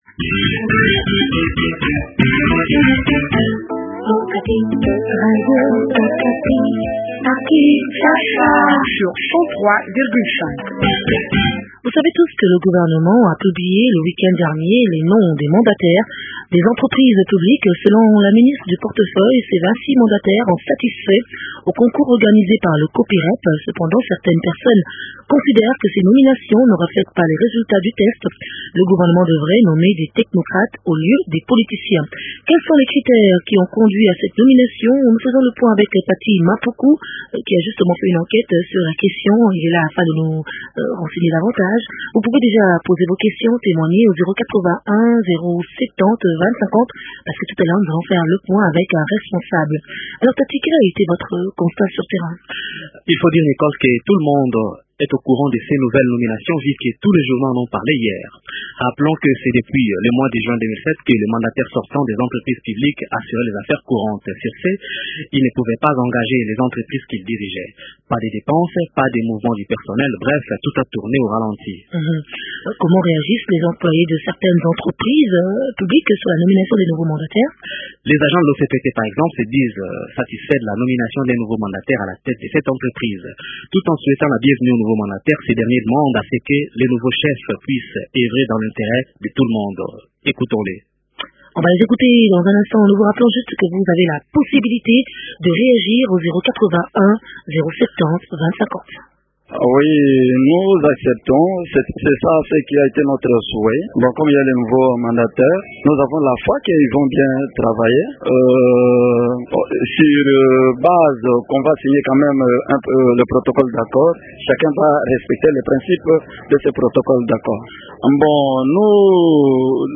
éclaire la lanterne au téléphone